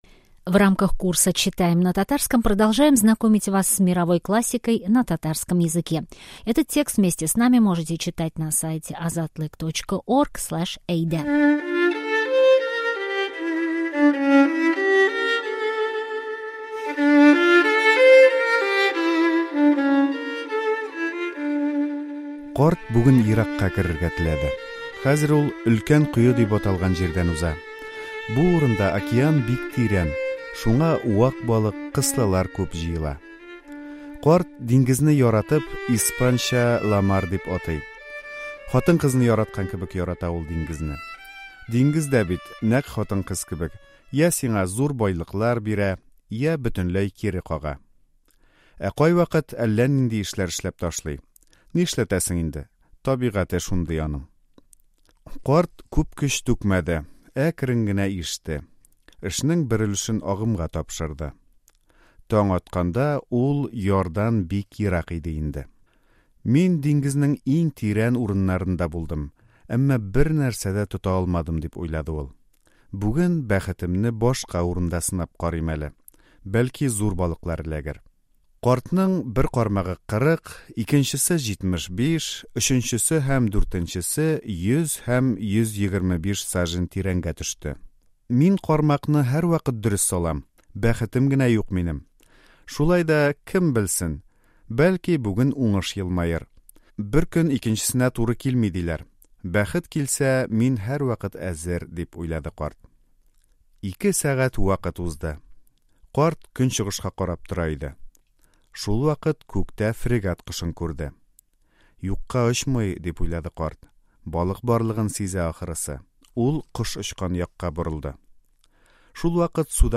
Продолжаем знакомиться с мировой классикой на татарском языке! Сегодня читаем всемирно известную повесть американского писателя Эрнеста Хемингуэя "Старик и море" (перевод Ясира Шамсутдинова).